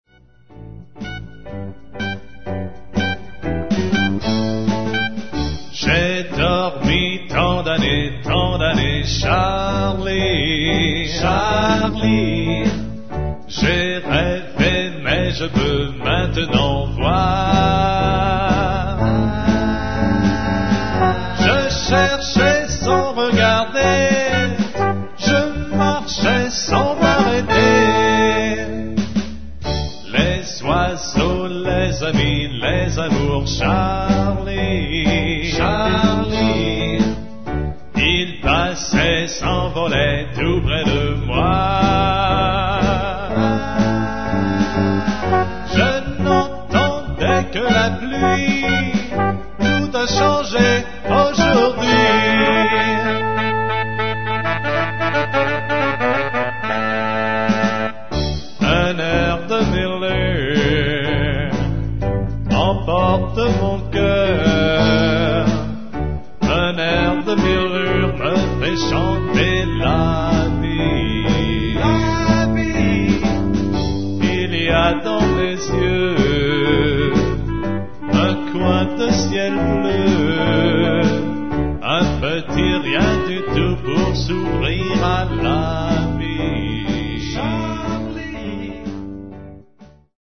Genre: Swing Année de réalisation: Technique utilisée: Histoire: Hommage � Glenn Miller Description de l'oeuvre: Pi�ce en langue fran�aise.